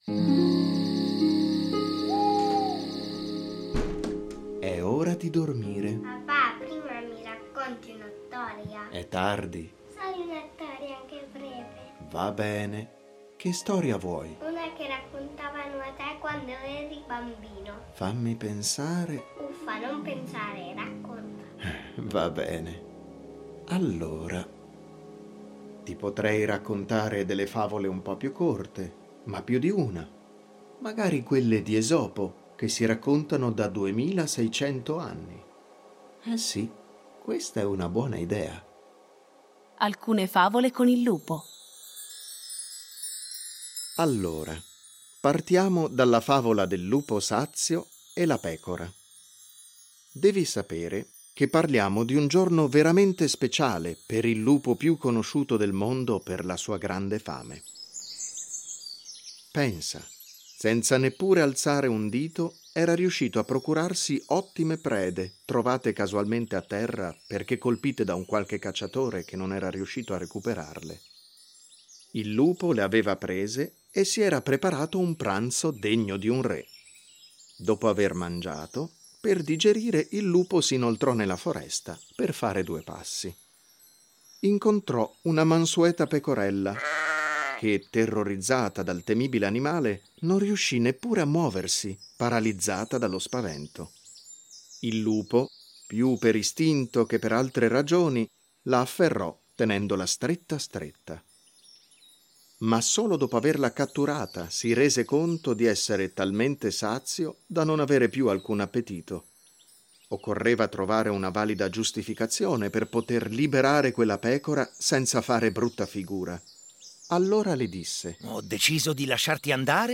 Fiabe
A partire dai testi originali un adattamento radiofonico per fare vivere ai bambini storie conosciute, ma un po’ dimenticate.